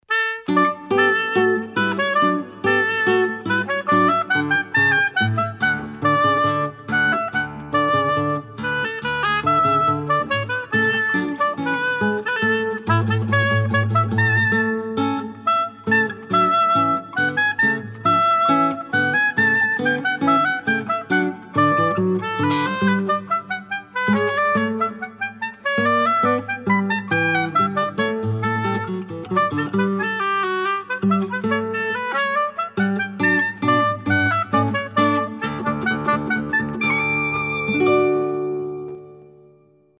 Classical:
Flute